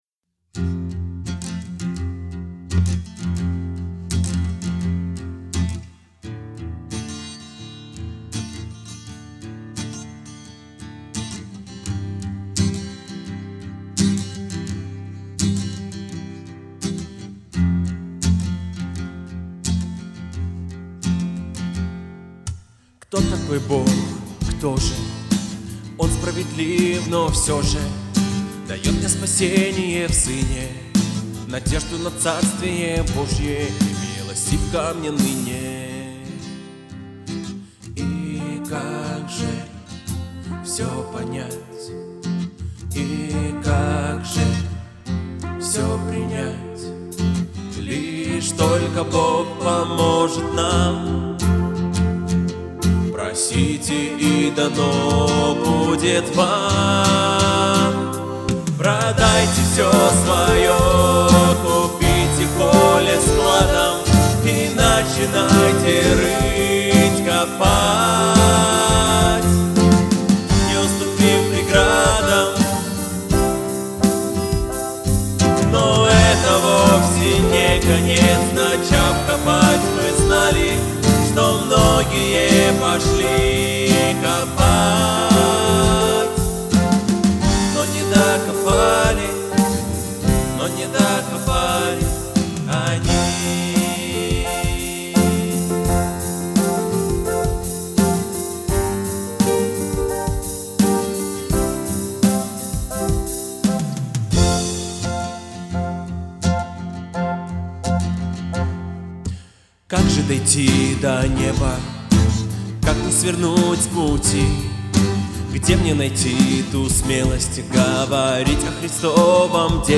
371 просмотр 91 прослушиваний 8 скачиваний BPM: 86